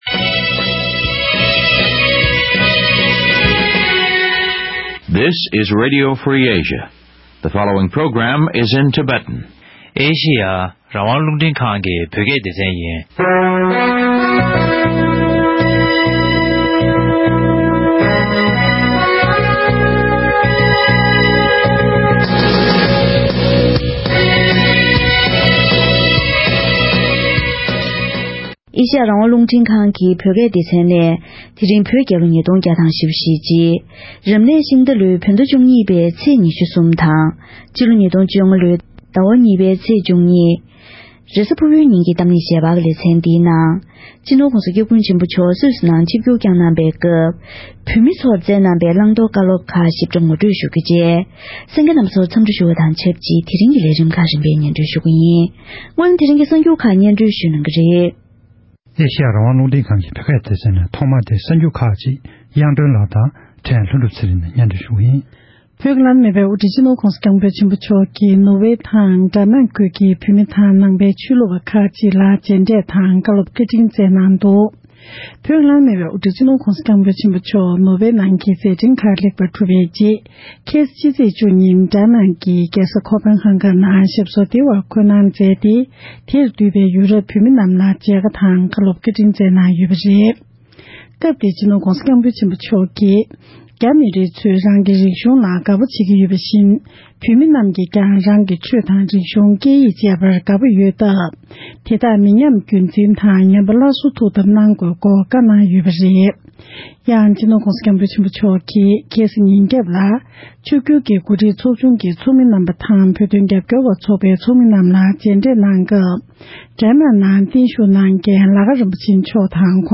༸གོང་ས་༸སྐྱབས་མགོན་ཆེན་པོ་མཆོག་ནས་སུད་སིར་བོད་མི་བདུན་སྟོང་ལྷག་ཙམ་ལ་མཇལ་ཁ་དང་བཀའ་སློབ་སྩལ་བ།